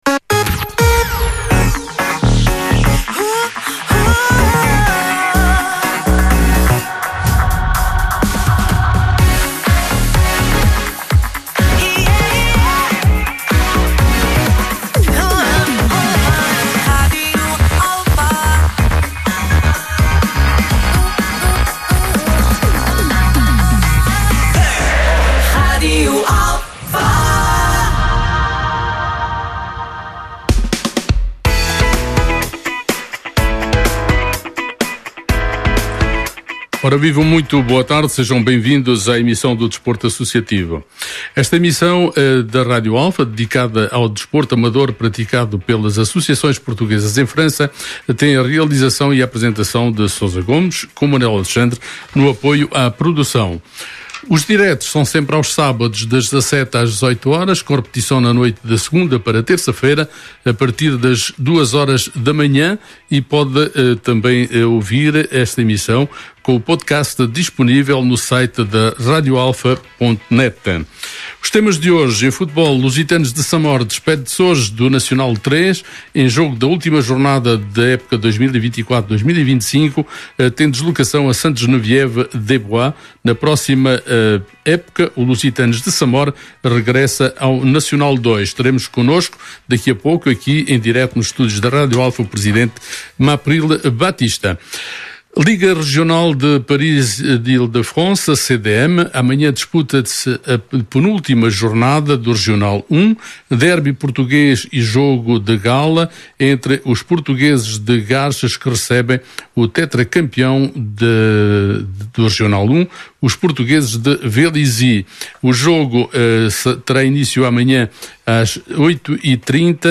com a presença em estúdio